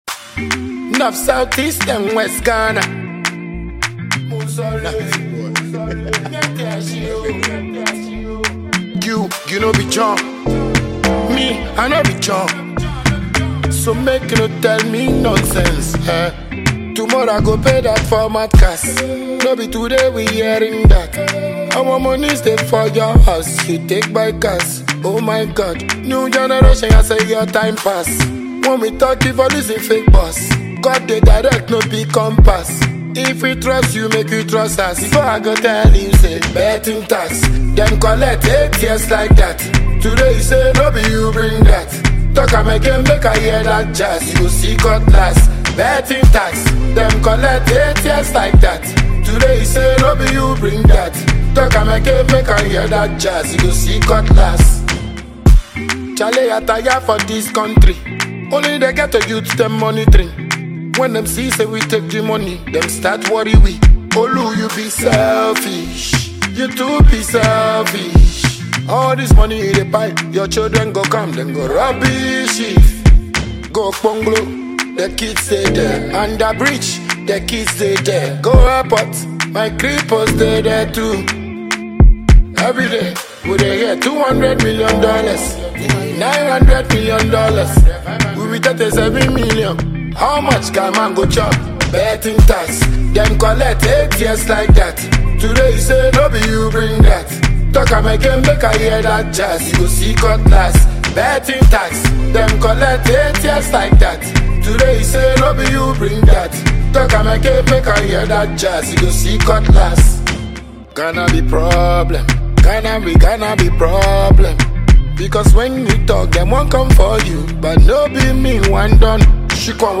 Ghanaian dancehall musician